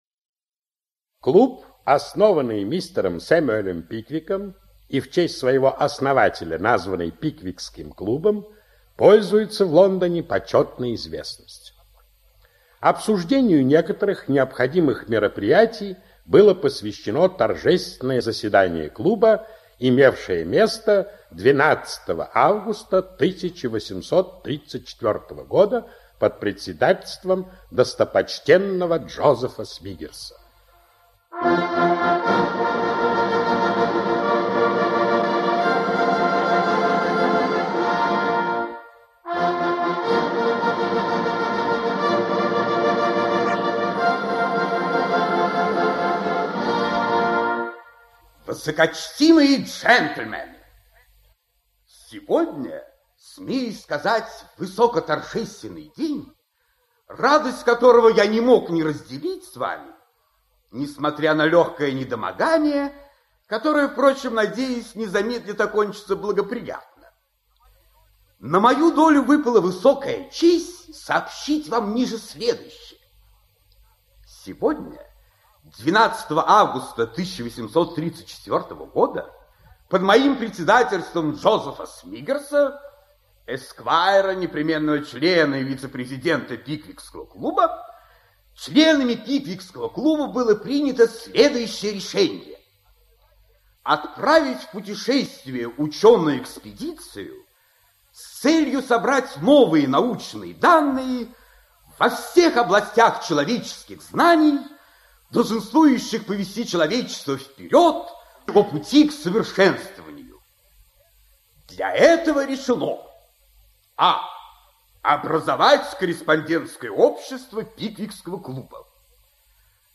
Аудиокнига Пиквикский клуб (спектакль) | Библиотека аудиокниг
Aудиокнига Пиквикский клуб (спектакль) Автор Чарльз Диккенс Читает аудиокнигу Анатолий Кторов.